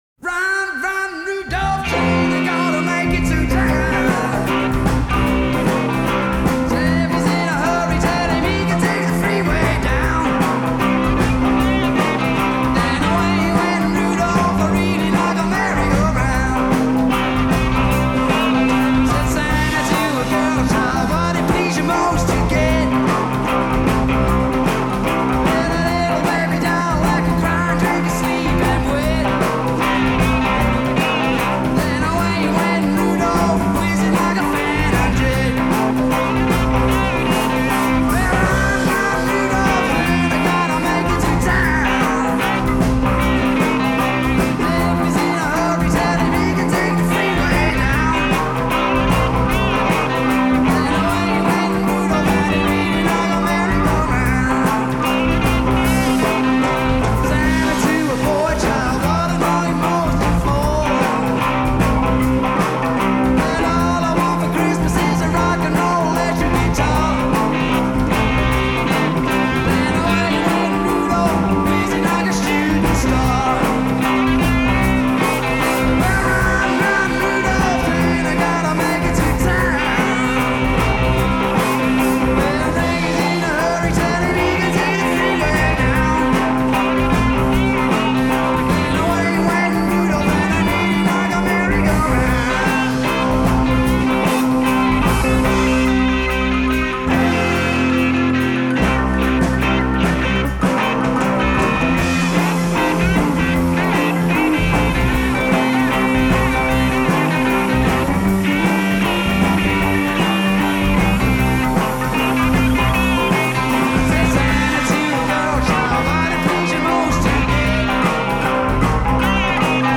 los villancicos más roqueros